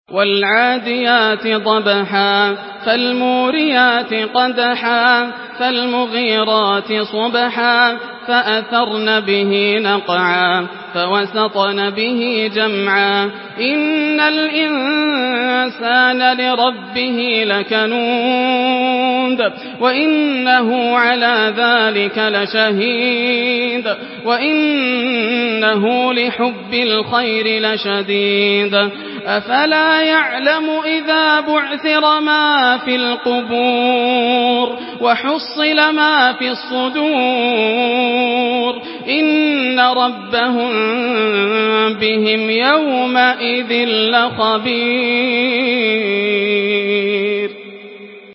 Surah العاديات MP3 by ياسر الدوسري in حفص عن عاصم narration.